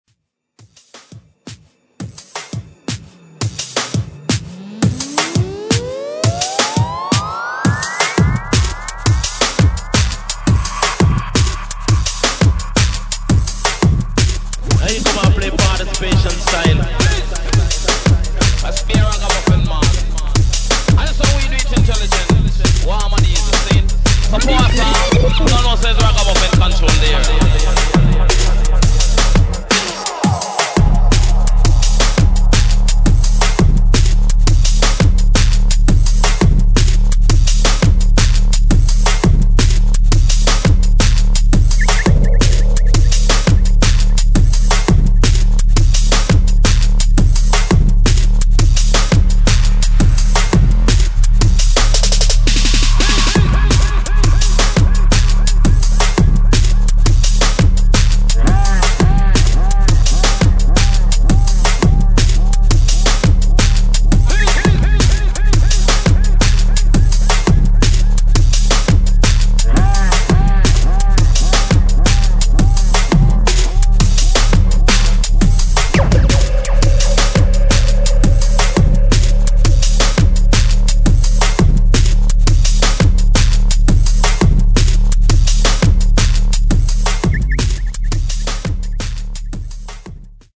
[ DUBSTEP / DRUM'N'BASS ]